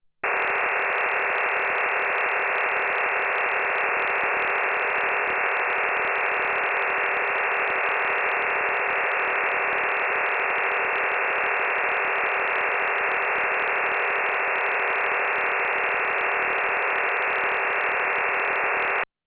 WINDRM transmitting digital data - audio samples
WinDRM 51-TONE OFDM MODEM (48-DATA CARRIERS + 3 REF. TONES - AMATEUR RADIO MODE) All material Copyright © 1998 - 2008 No content on this website may be used or published without written permission of the author!